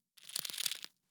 Rustle0.ogg